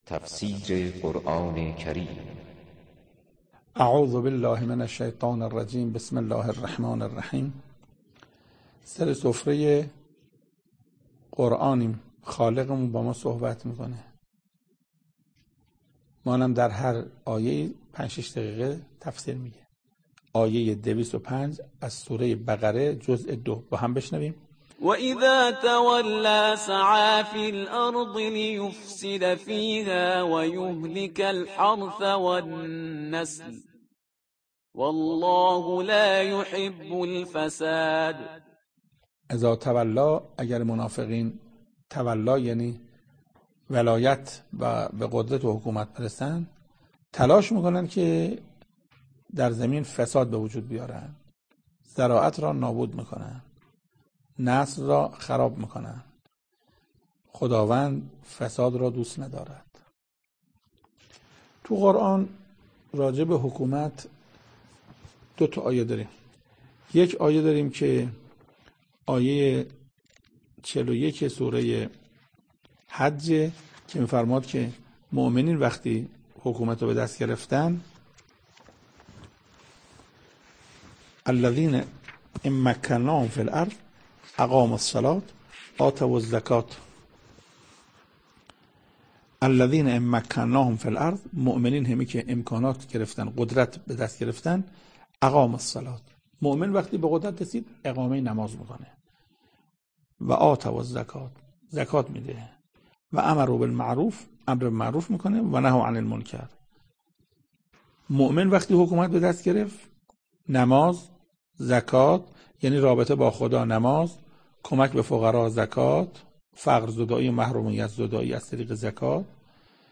تفسیر صوتی
سخنرانی محسن قرائتی